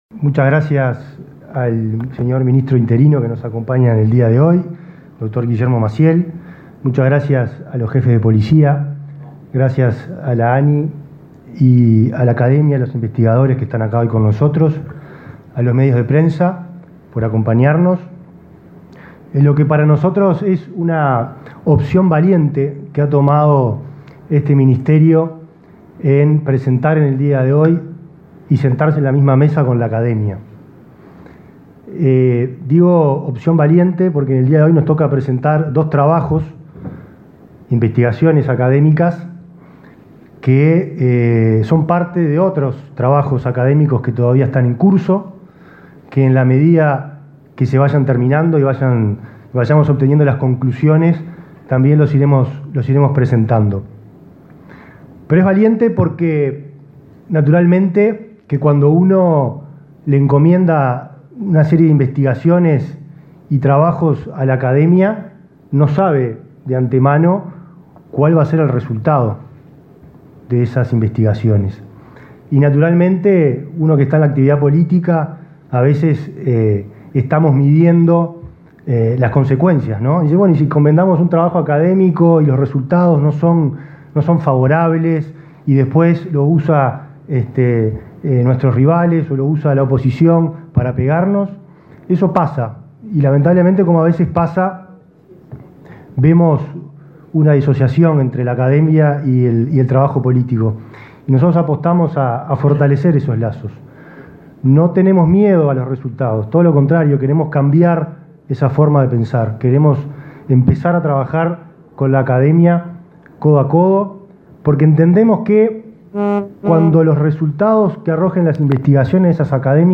Palabras de autoridades en el Ministerio del Interior
Palabras de autoridades en el Ministerio del Interior 10/07/2023 Compartir Facebook X Copiar enlace WhatsApp LinkedIn El director general del Ministerio del Interior, Nicolás Martinelli, y el presidente de la Agencia Nacional de Investigación e Innovación (ANII), Flavio Caiafa, participaron en el acto de presentación de resultados de investigaciones sobre crímenes.